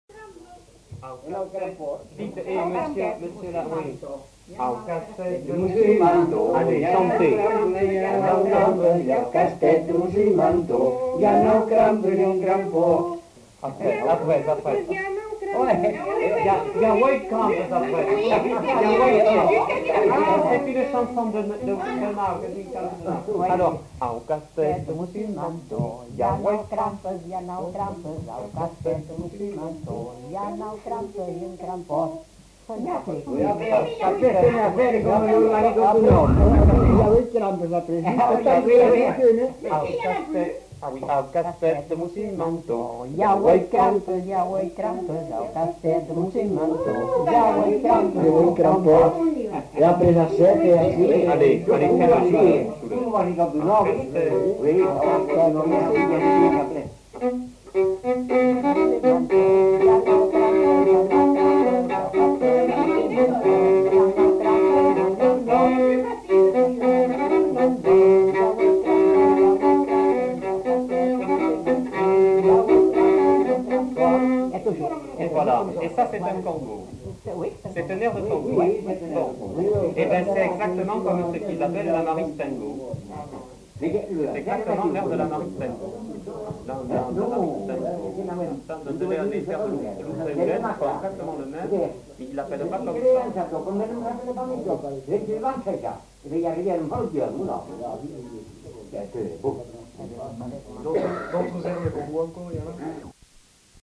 Aire culturelle : Grandes-Landes
Genre : chant
Type de voix : voix mixtes
Production du son : chanté
Danse : congo
Notes consultables : Interprété au violon par l'enquêteur à la fin de la séquence.